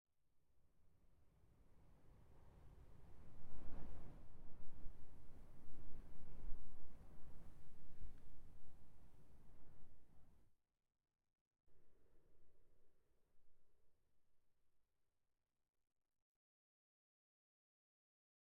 sounds / block / sand / wind3.ogg
wind3.ogg